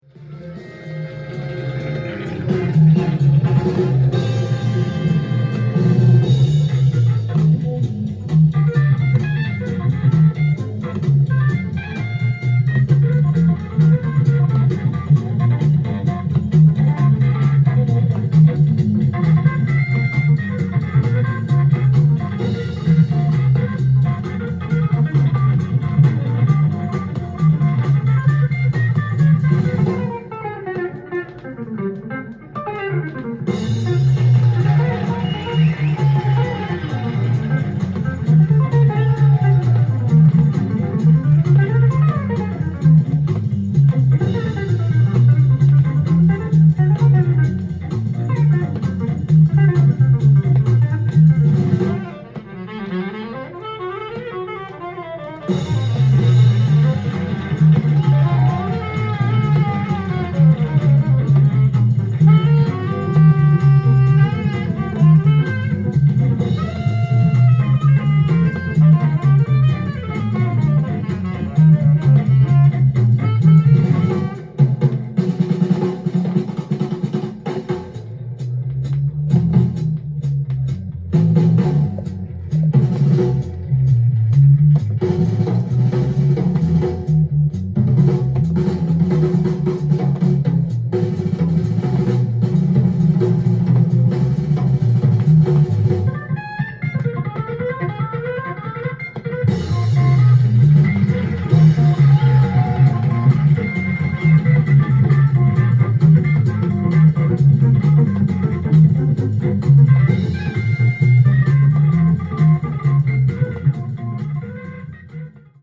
店頭で録音した音源の為、多少の外部音や音質の悪さはございますが、サンプルとしてご視聴ください。
サンフランシスコ「ジャズ・ワークショップ」でのライブ録音。
オープニングを飾るスィンギー&ファンキーな